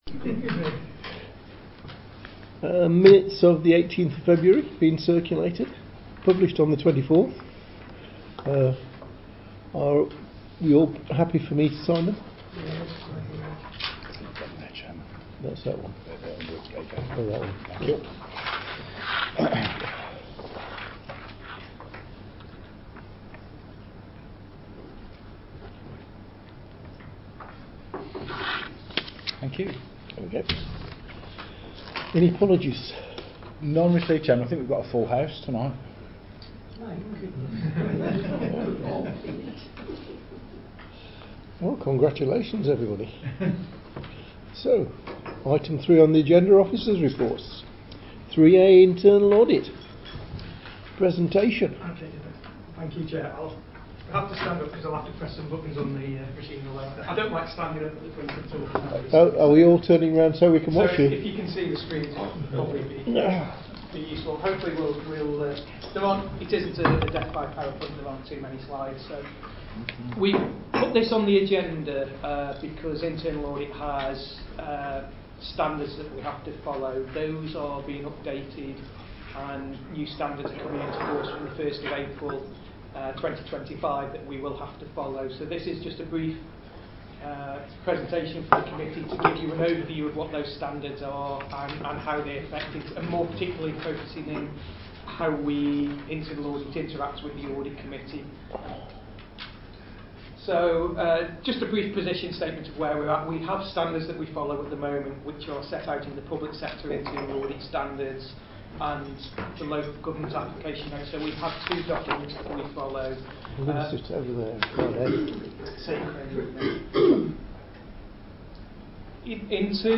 Location: Craddock Room, Civic Centre, Riverside, Stafford